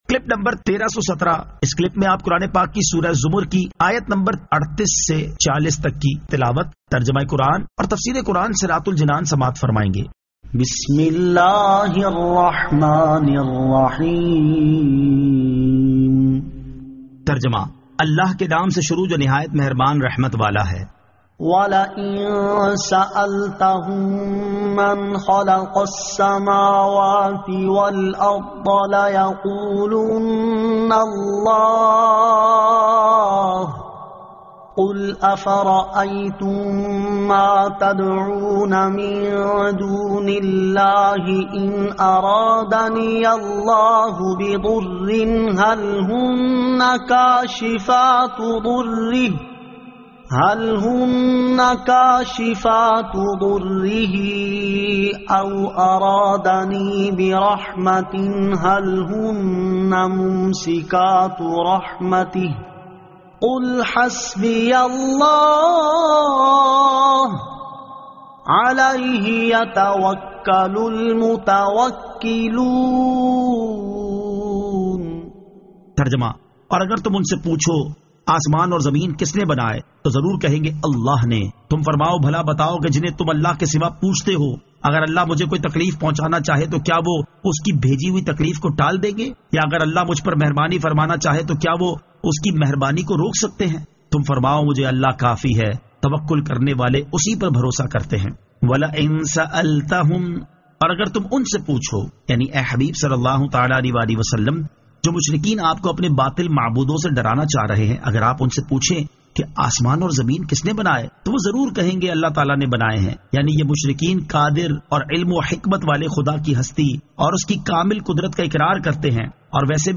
Surah Az-Zamar 38 To 40 Tilawat , Tarjama , Tafseer